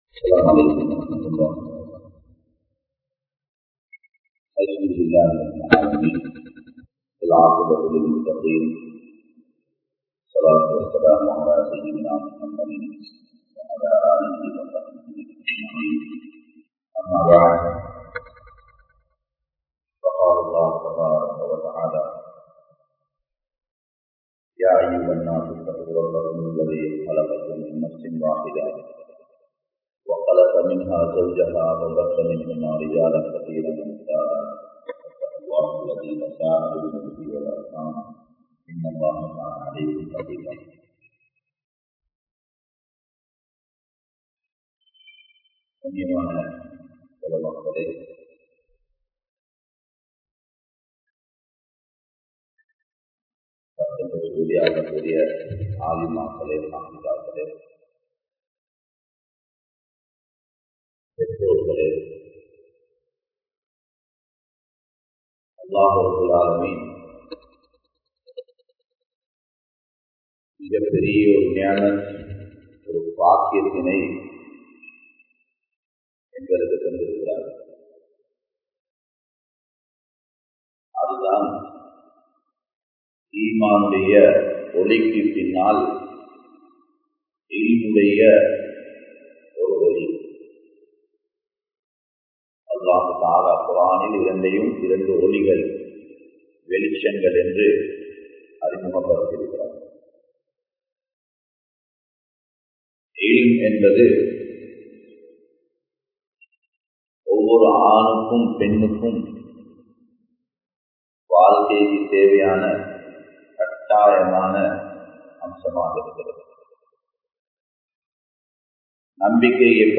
Islamiya Pengal | Audio Bayans | All Ceylon Muslim Youth Community | Addalaichenai